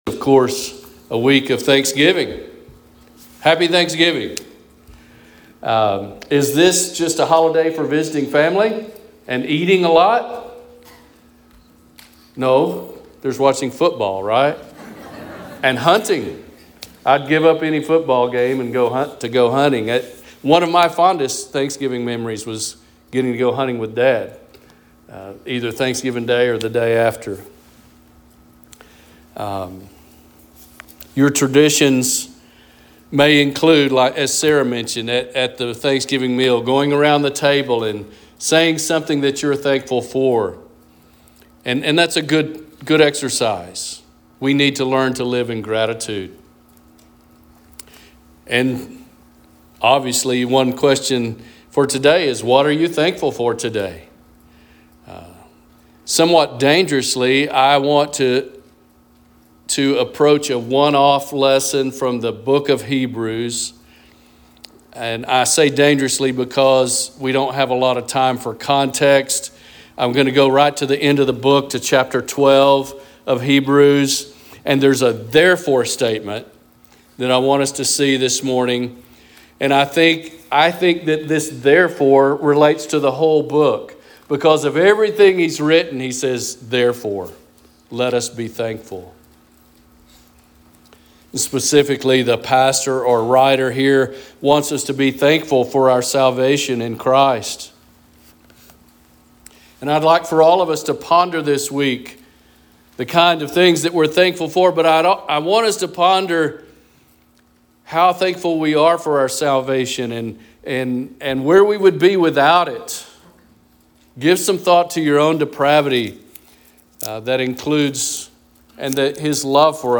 Sermons | Lawn Baptist Church